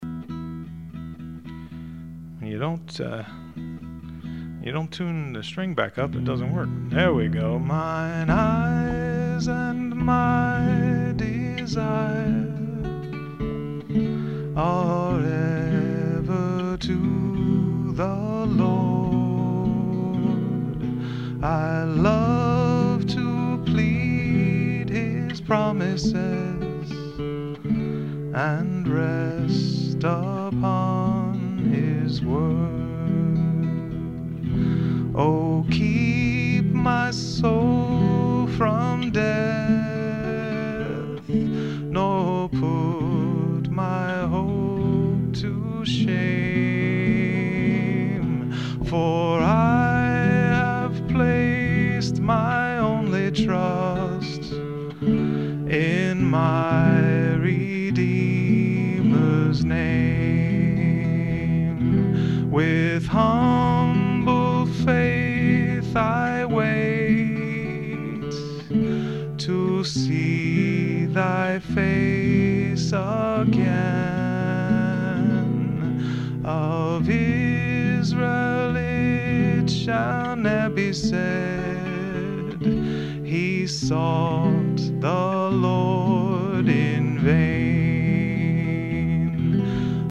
Voice and Guitar- The Bible Book Nook, Vineland, NJ